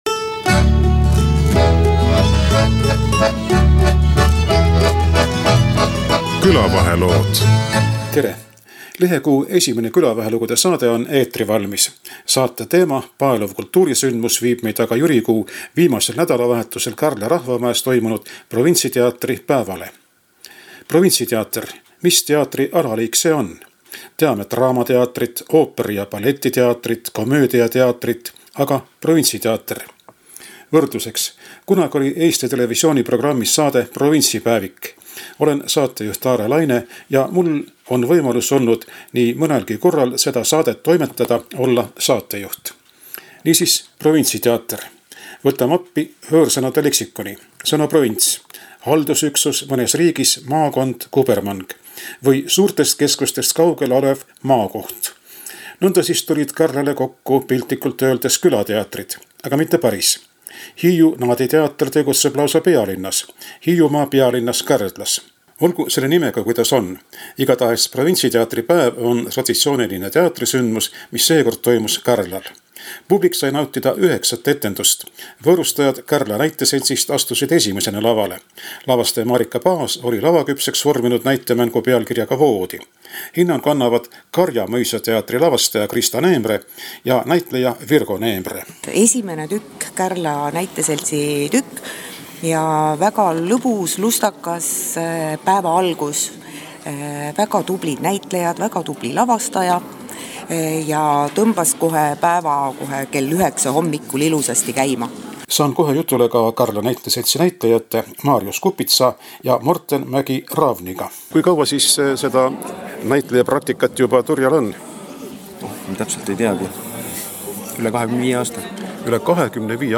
Publik sai nautida üheksat etendust, Lisaks Saaremaa teatritruppidele olid osalejate hulgas hiidlased ja harrastusnäitlejad Pärnumaalt. Raadiomees küsitleb nii näitlejaid, lavastajaid kui ka inimesi publiku hulgast.